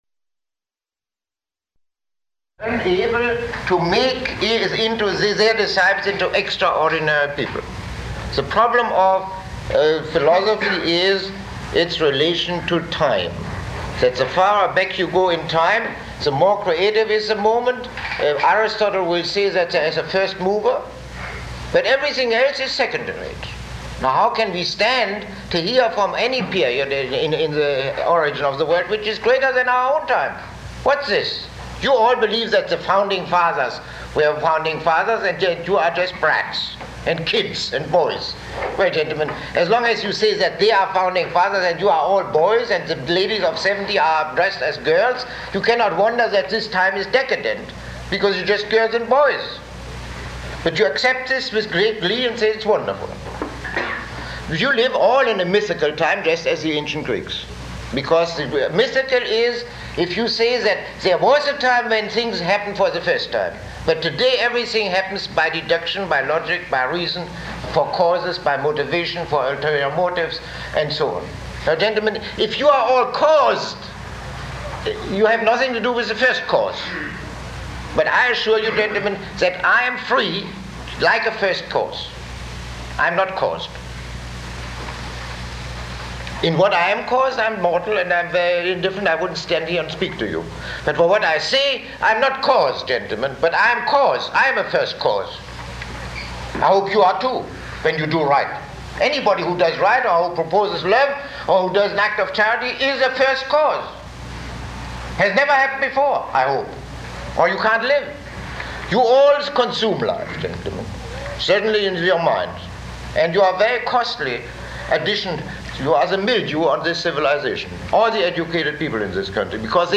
Lecture 25